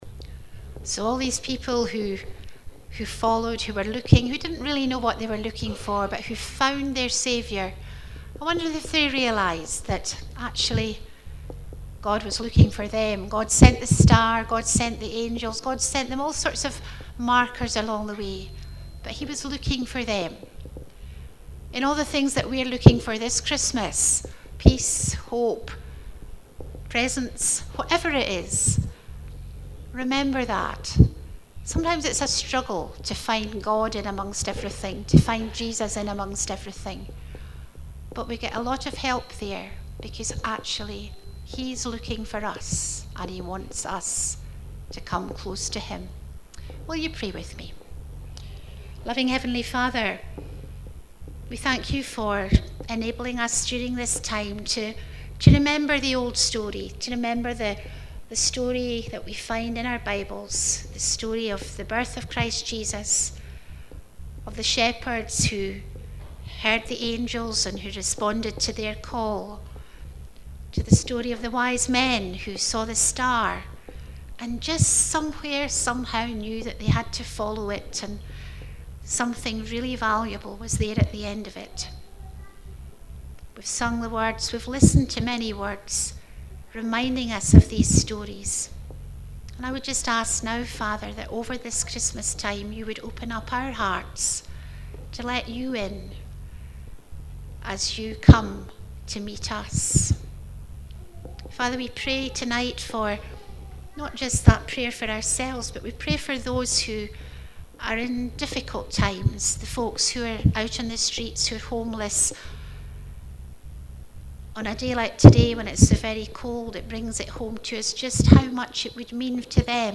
Fourth Advent Afternoon Family Service
prayer and closed with singinig 'O little town of Bethlehem'  and the blessing.